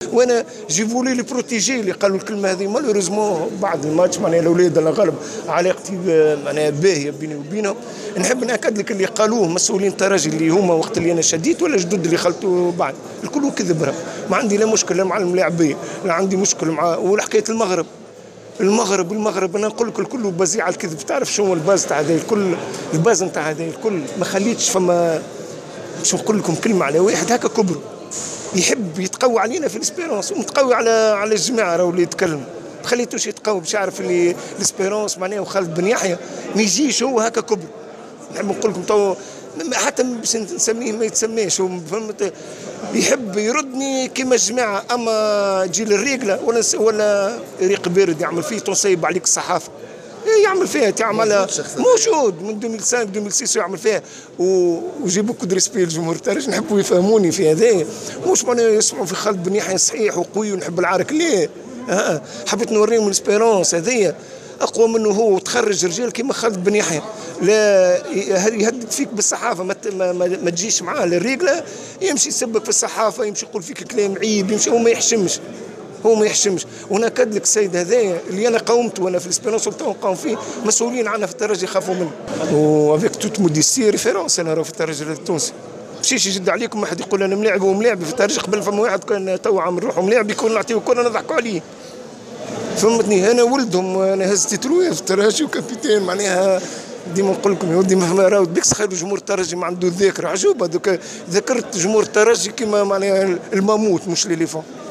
خالد بن يحي : مدرب قوافل قفصة